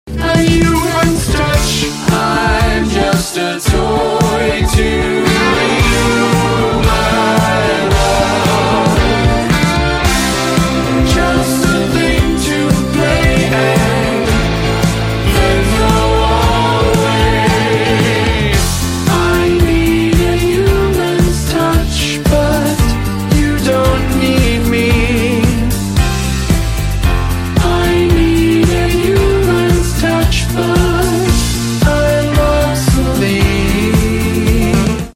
General Electric Medical Optima 660/520 sound effects free download
General Electric Medical Optima 660/520 CT Scanner my beloved. Your audio relaxes me, your contrast warms me up, your ability to see all of me is so loving.